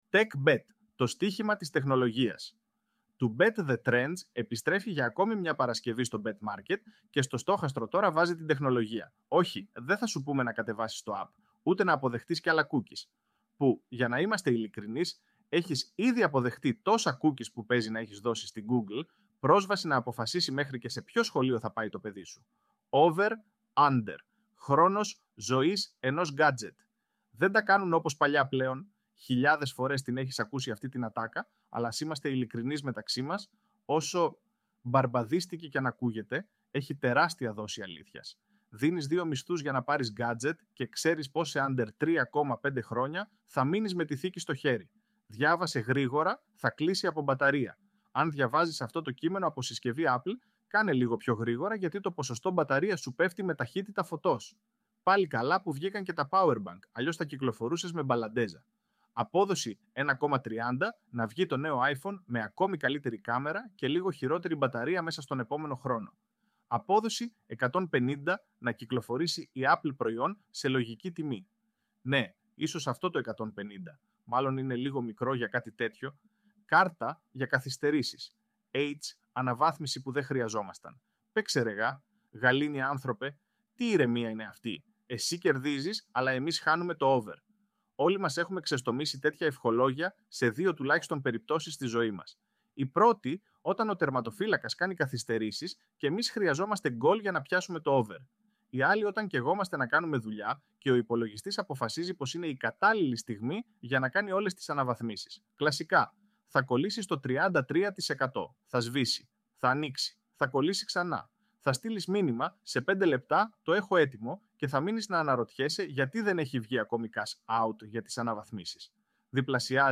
Tωρα μπορείς να ακούσεις τη στήλη με τη βοήθεια του Ai!